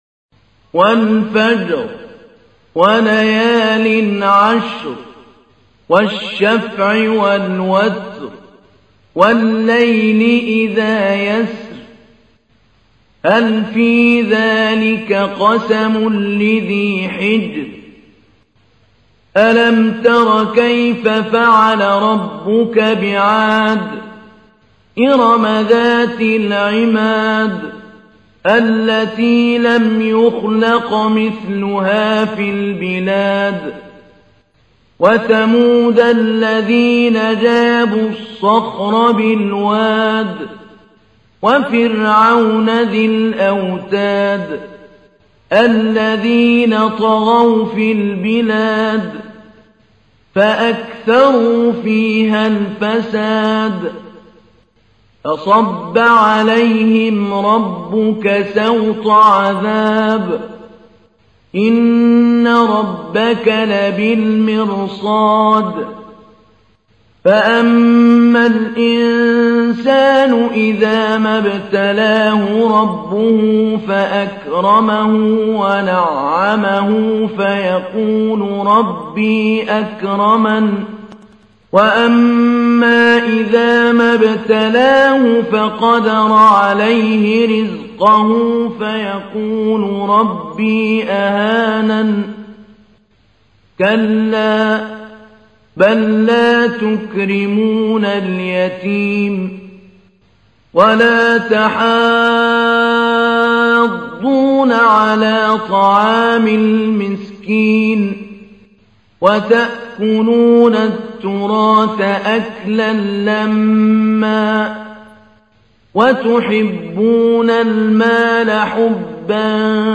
تحميل : 89. سورة الفجر / القارئ محمود علي البنا / القرآن الكريم / موقع يا حسين